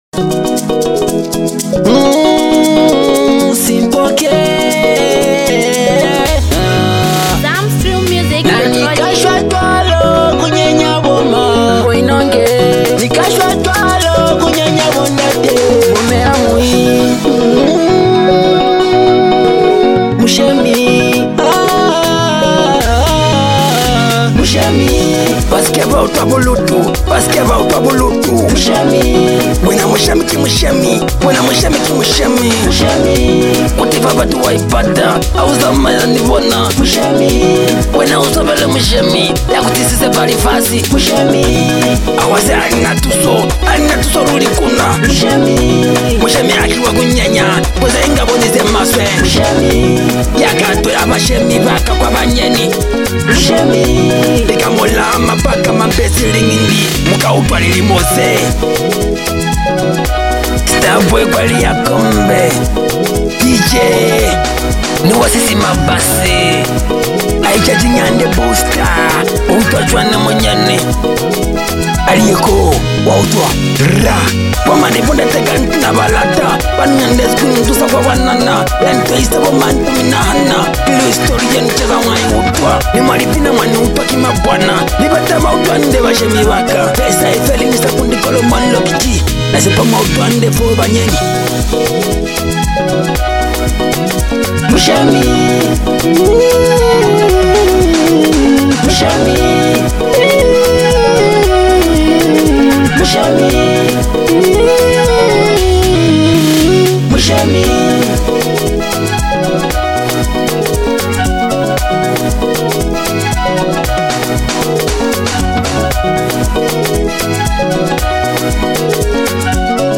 With smooth melodies, relatable lyrics, and rich production
Afro-inspired sounds with local influence